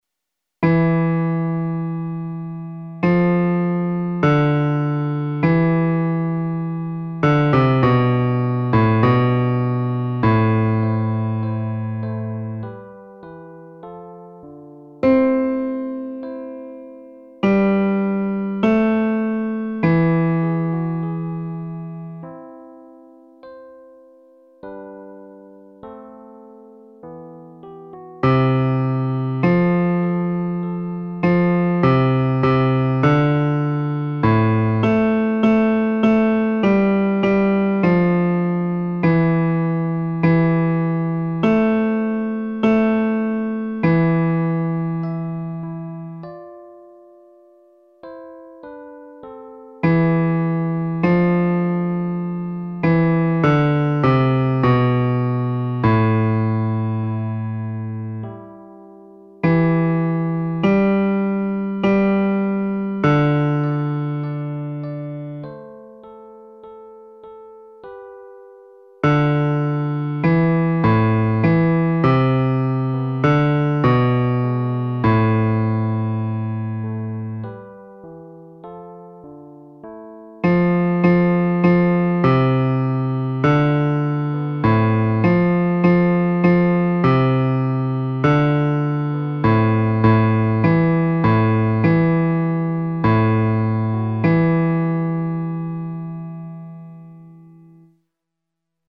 Audios para estudio (MP3)
Bajo